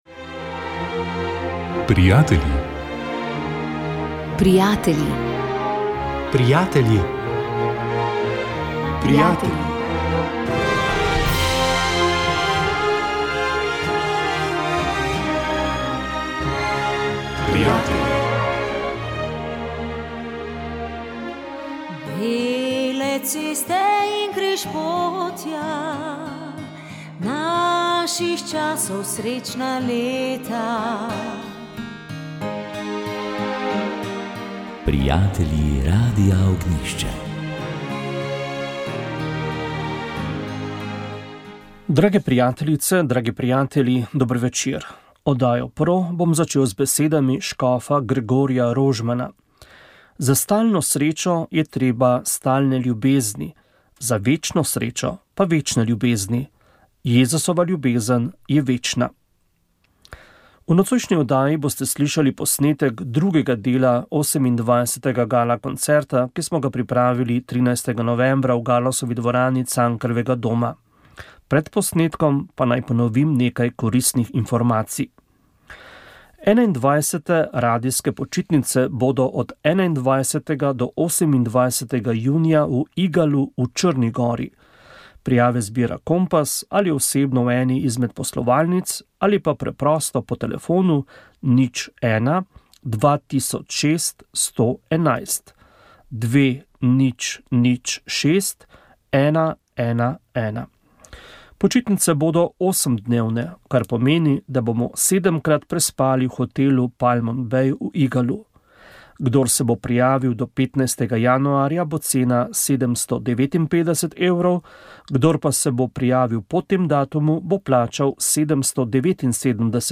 V oddaji ste slišali posnetek 2. dela 28. gala koncerta. Soliste in soliste je spremljal Orkester Slovenske vojske.